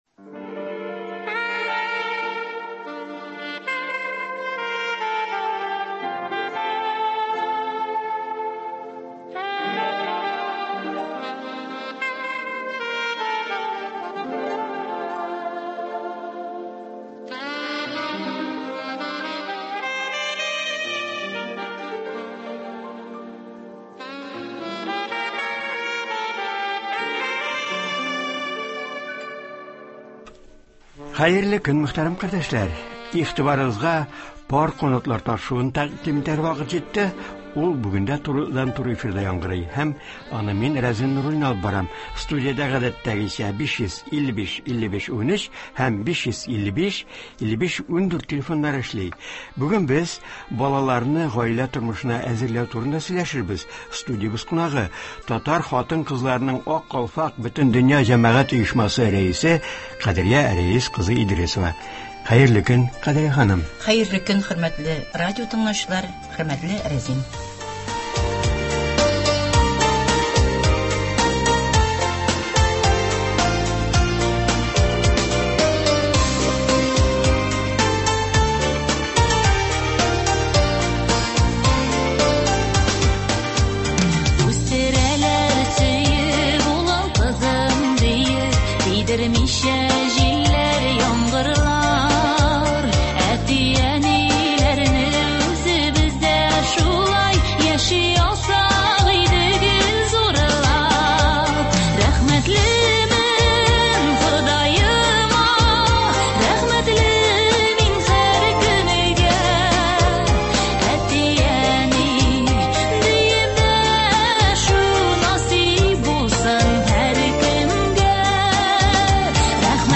Болар хакында турыдан-туры эфирда
телефон аша радиотыңлаучылар белән аралашачак